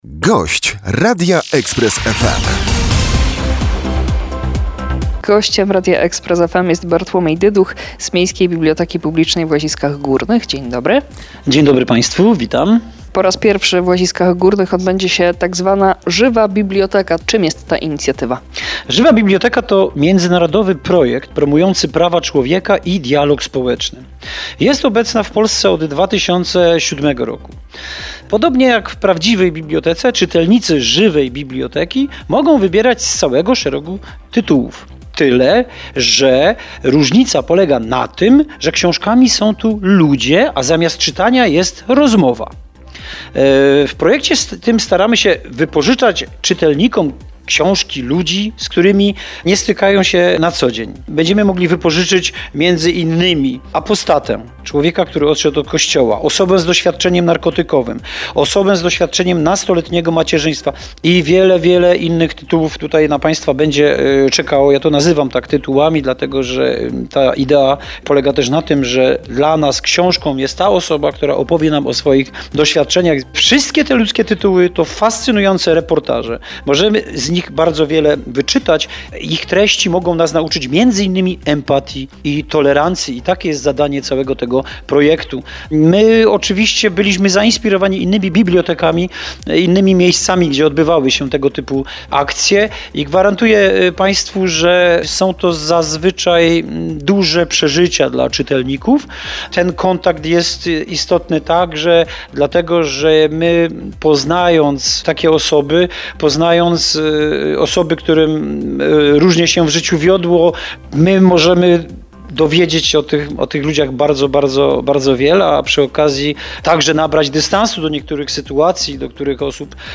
zywe_ksiazki_gosc_podklad_1704_ns.mp3